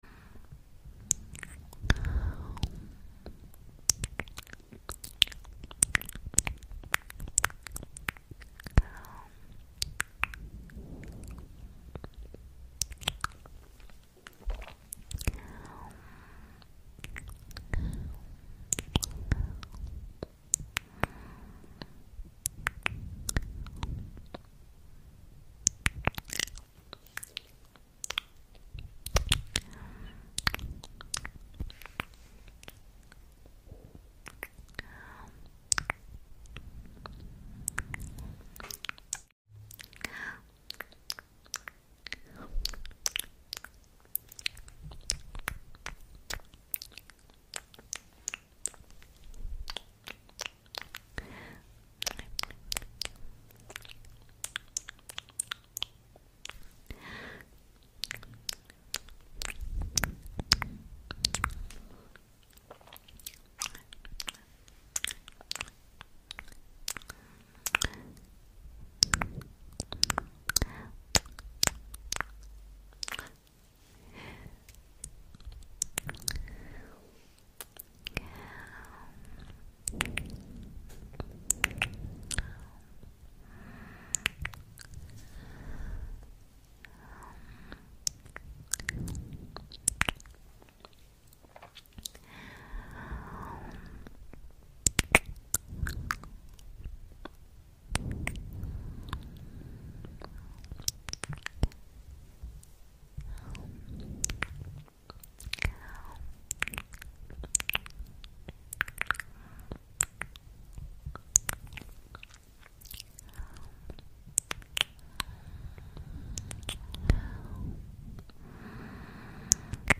ASMR MOUTH SOUNDS 👅 Sound Effects Free Download